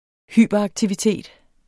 Udtale [ ˈhyˀbʌˌ- ]